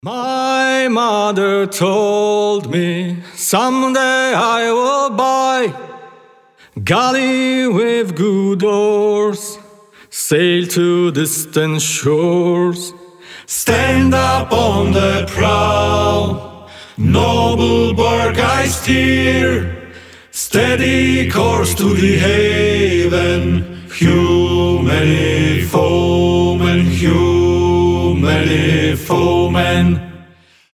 • Качество: 320, Stereo
брутальные
спокойные
хор
акапелла